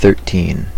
Ääntäminen
US : IPA : [ˌθɝ.ˈtin] UK : IPA : /ˈθɜː.tiːn/ IPA : /ˌθɜː.ˈtiːn/ US : IPA : /ˈθɝ.tin/